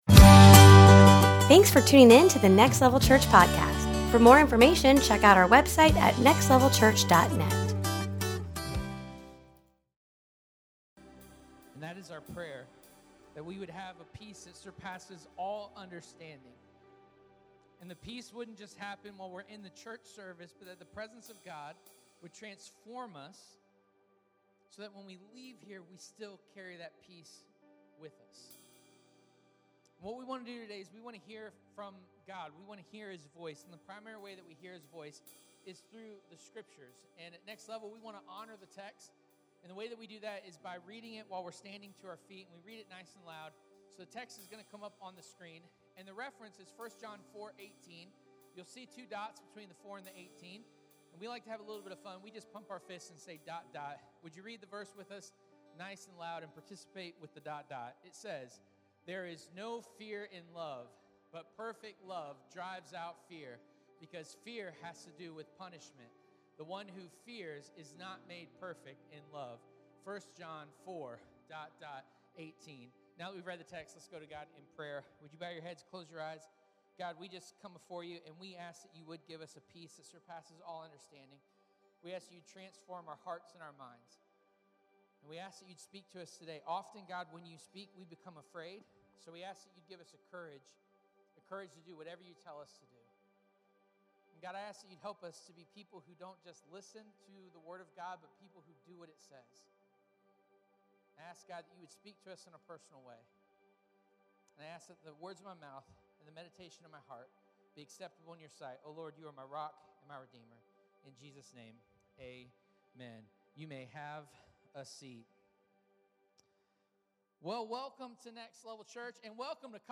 Fighting Fear Passage: 1 John 4:18 (NIV) Service Type: Sunday Morning Topics